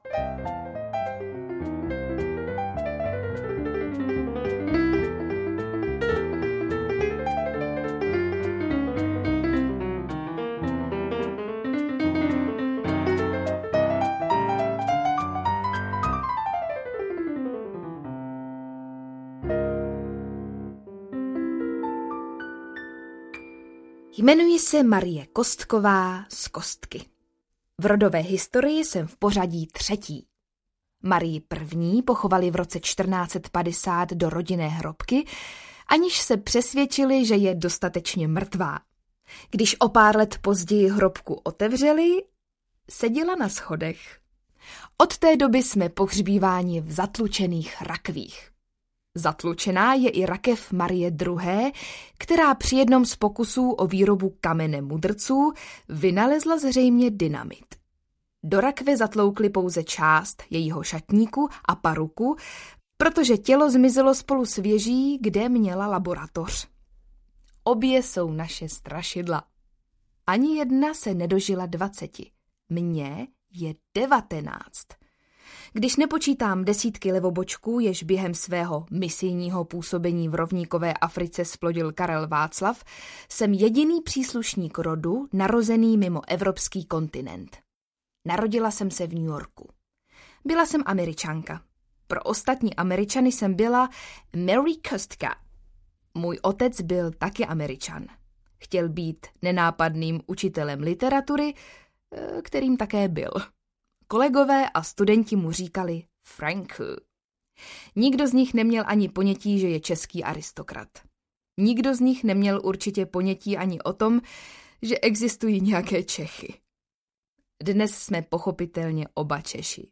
Poslední Aristokratka audiokniha
Ukázka z knihy
Nejlepší humoristická kniha roku 2012 (Cena Miloslava Švandrlíka) Audiokniha líčí příběh emigrantské rodiny Kostků, která v restituci získá zpět své zchátralé rodové sídlo a je nucena se potýkat s nástrahami v podobě nedostatečných financí, mufloních návštěvníků, mizantropického kastelána, hypochondrického zahradníka nebo kuchařkou, která si ráda občas cvakne a někdy je to znát. Veškeré peripetie posluchači předkládá ve svých ironických zápiscích Marie (III.) v úsměvném podání Veroniky Kubařové coby dcery škudlivého hraběte a jeho americké manželky.
• InterpretVeronika Khek Kubařová